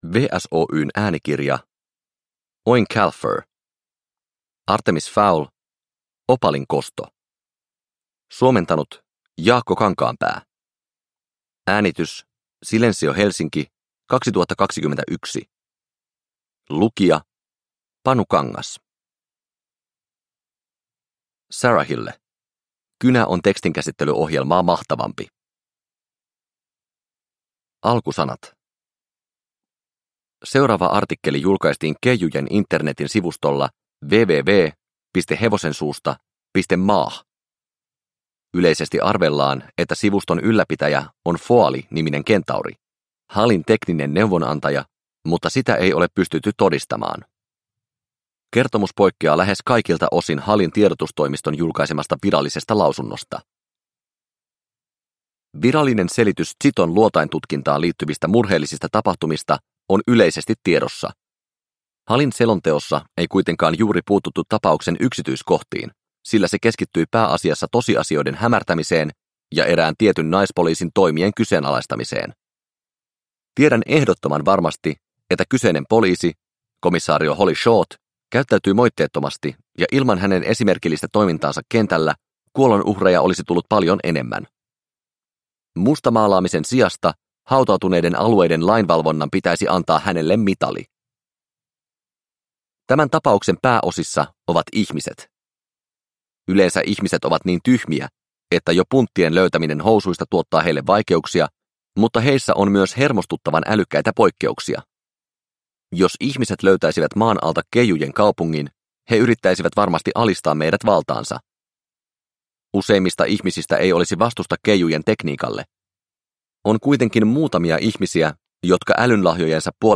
Artemis Fowl: Opalin kosto – Ljudbok – Laddas ner